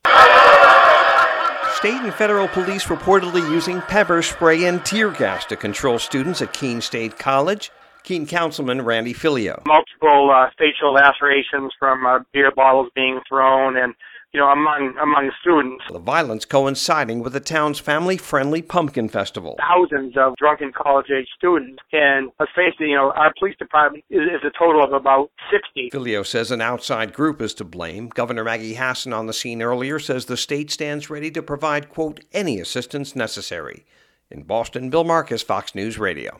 KEENE, NEW HAMPSHIRE COUNCILMAN RANDY FILIAULT SAYS HE IS HOPING WHEN HE WAKES UP IN THE MORNING THAT HE DOESN’T HEAR THAT ANY OF THE FIRST RESPONDERS WERE INJURED.